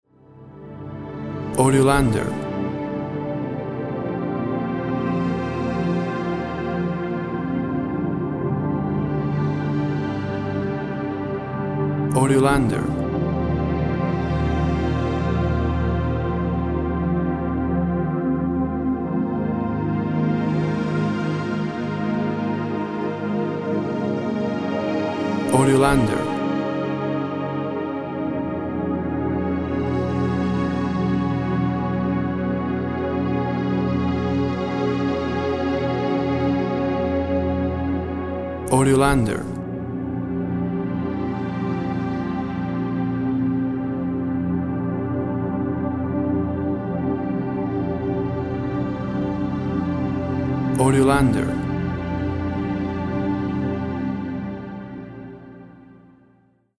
Thick synth sounds.
WAV Sample Rate 24-Bit Stereo, 44.1 kHz
Tempo (BPM) 56